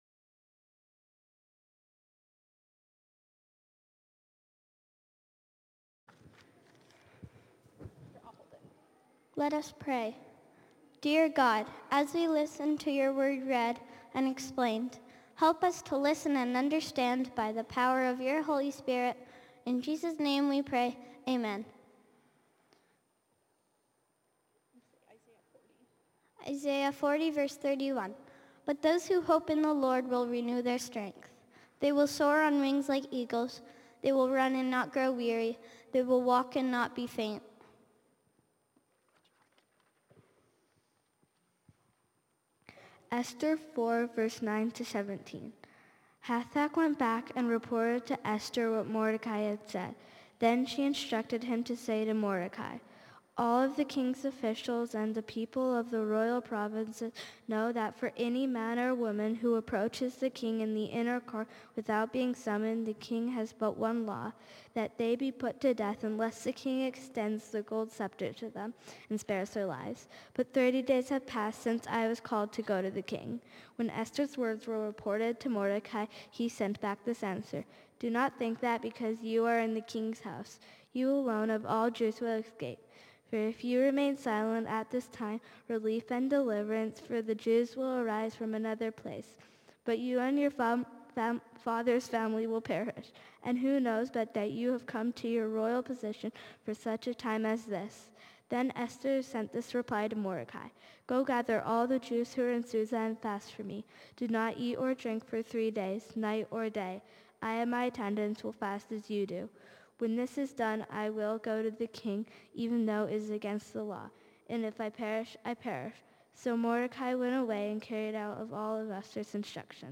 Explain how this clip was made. Esther 4:9-17 Service Type: Sunday Morning « Easter joy!